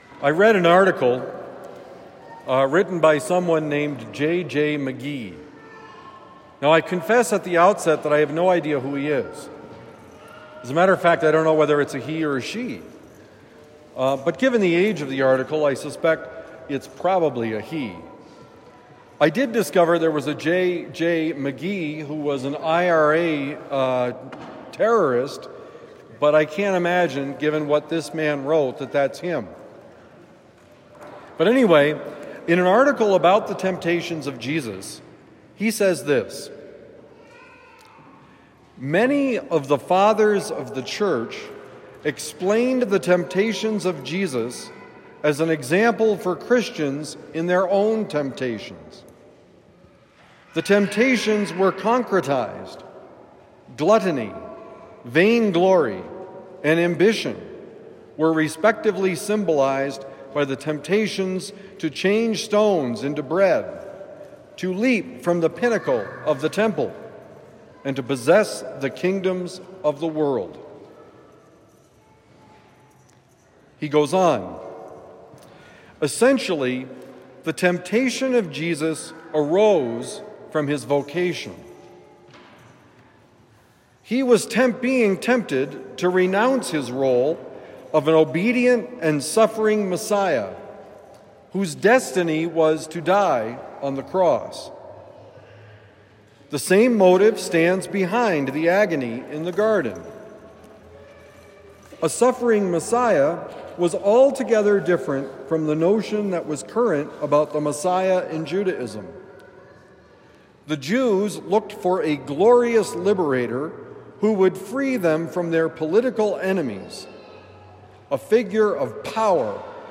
Fighting Temptations During Lent: Homily for Sunday, March 9, 2025